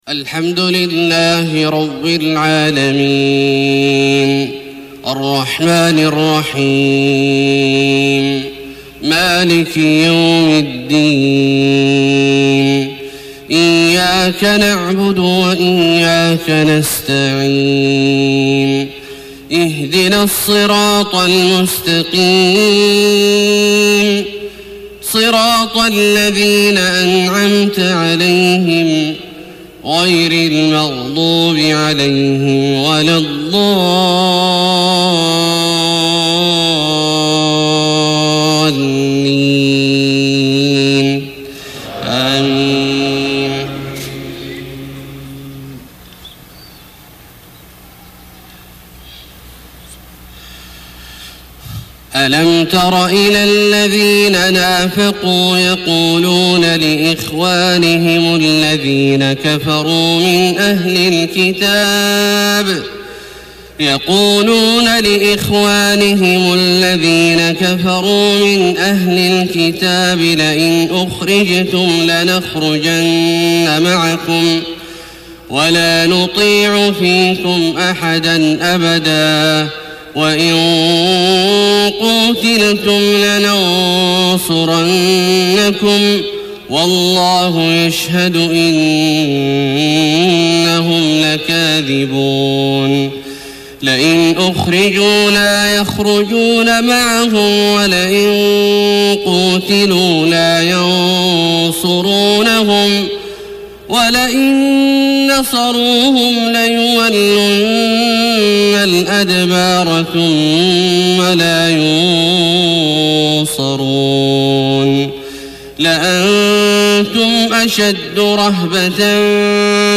جودة جميلة ماشاء الله تباركـ الله